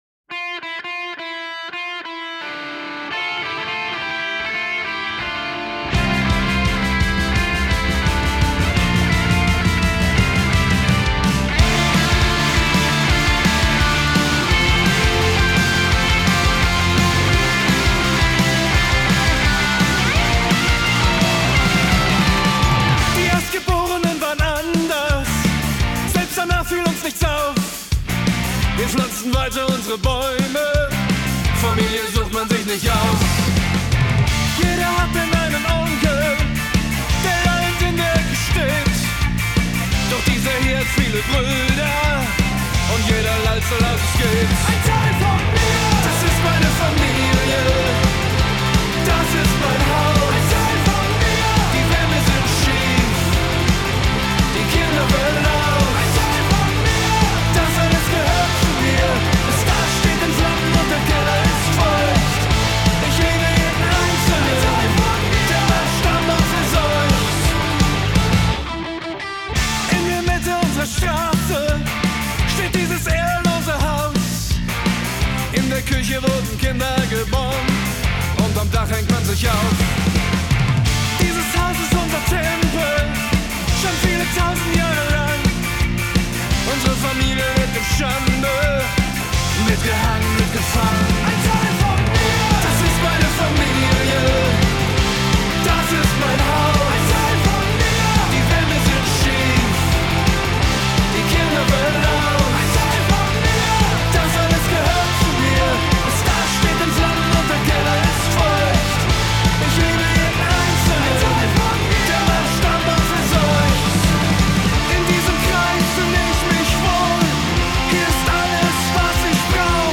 Rock GER